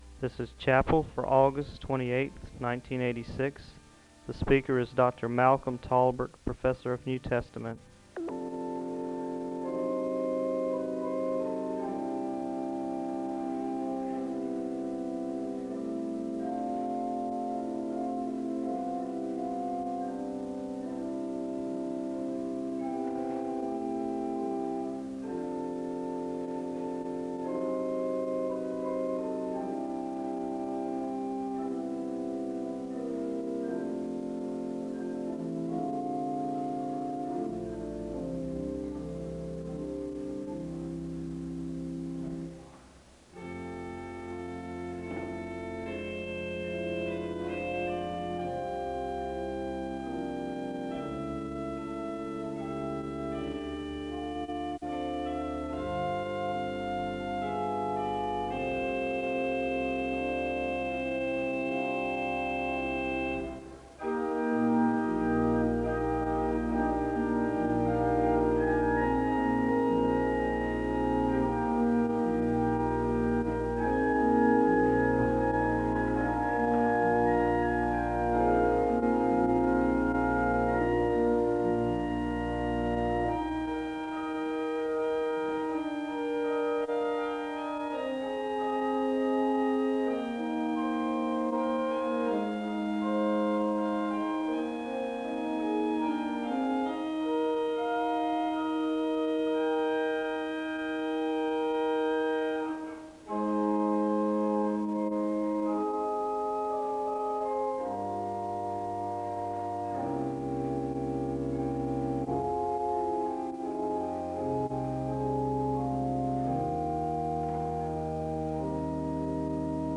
SEBTS Chapel
The service begins with organ music (0:00-5:32). There is a responsive reading (5:33-7:44).